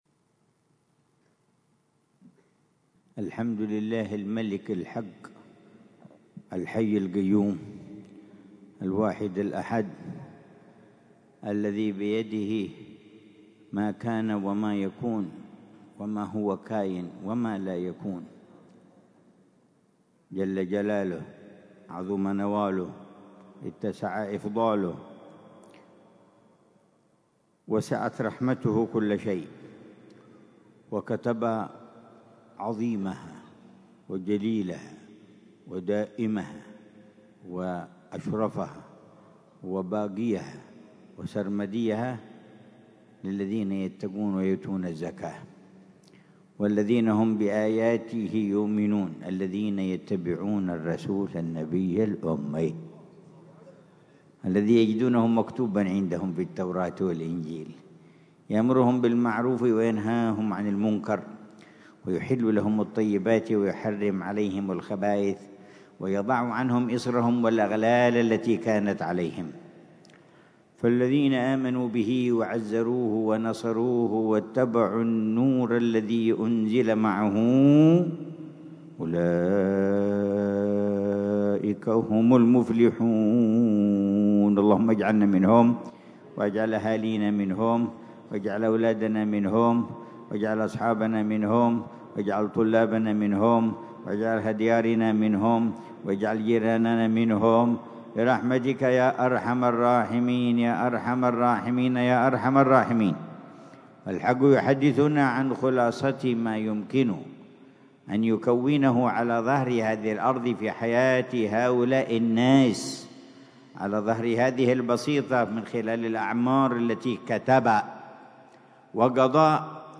محاضرة العلامة الحبيب عمر بن محمد بن حفيظ ضمن سلسلة إرشادات السلوك ليلة الجمعة 30 جمادى الأولى 1447هـ في دار المصطفى، بعنوان: